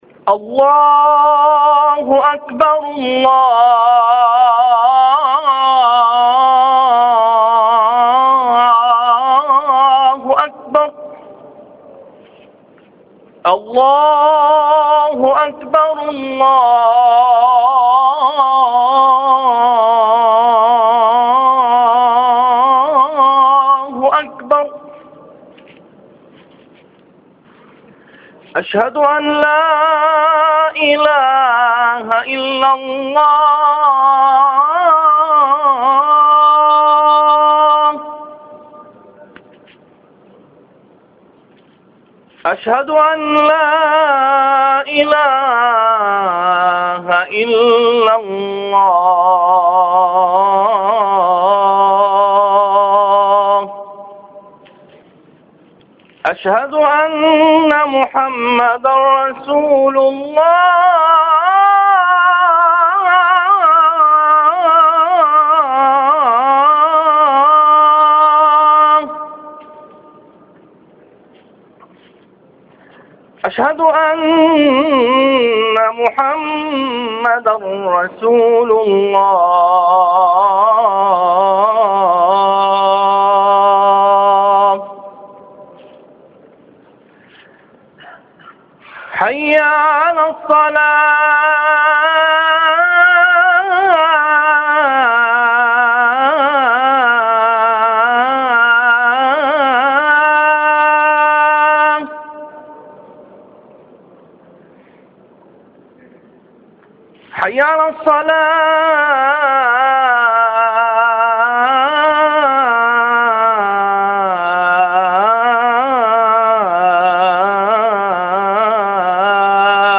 الأذان